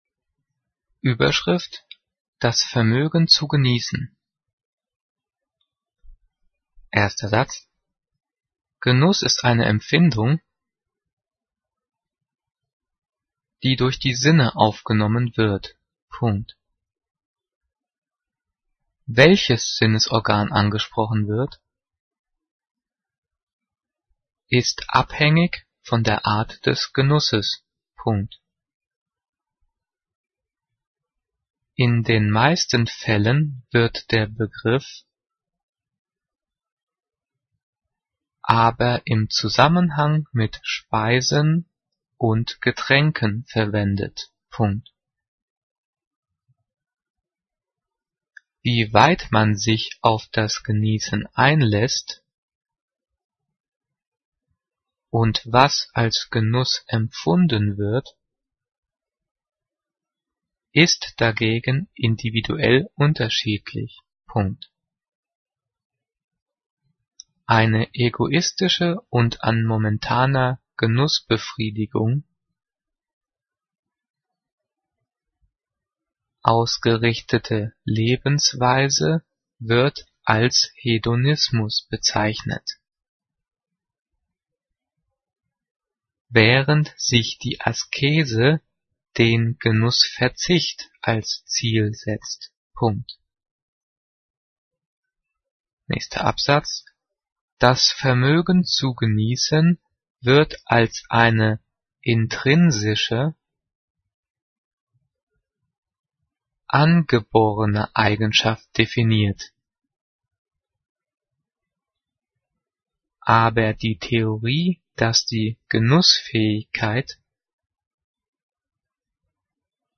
Die vielen Sprechpausen sind dafür da, dass du die Audio-Datei pausierst, um mitzukommen.
Diktiert: